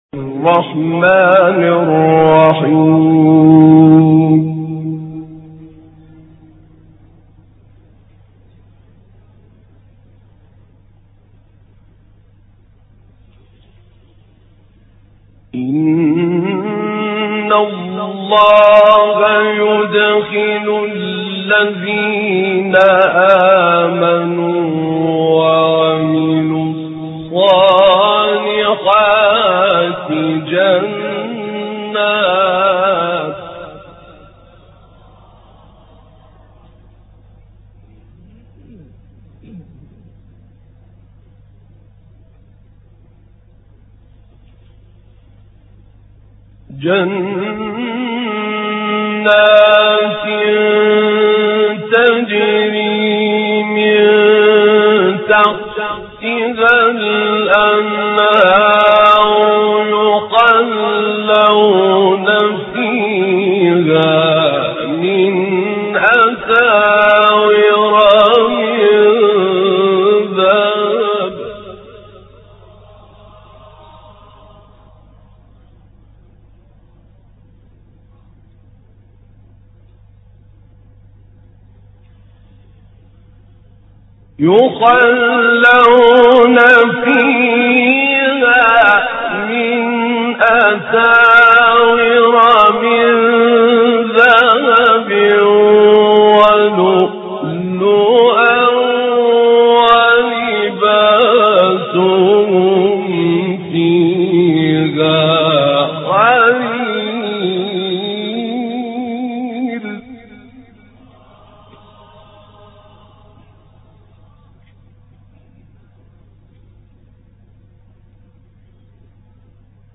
آیاتی از سوره حج با تلاوت «طاروطی»
گروه شبکه اجتماعی: تلاوت آیاتی از سوره حج با صوت عبدالفتاح طاروطی ارائه می‌شود.